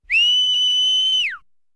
Звук пирата, свистящего при виде земли